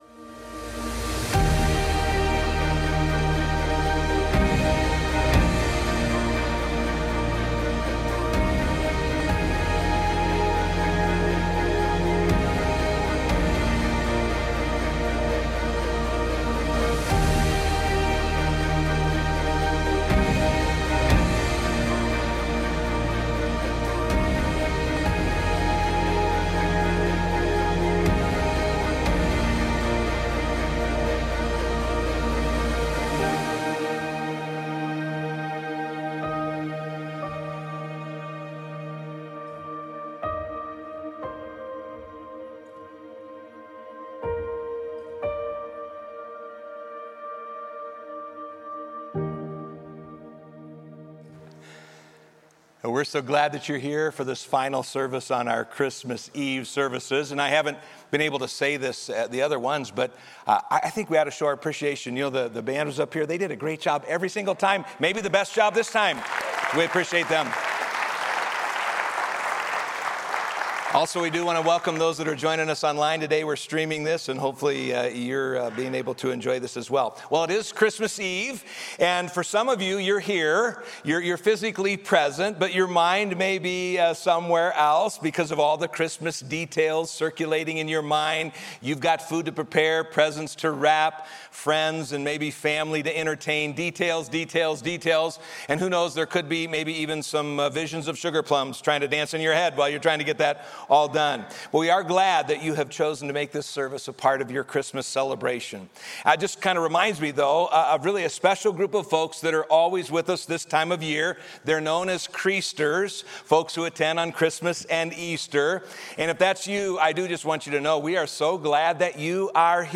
A message from the series "What's in a Name."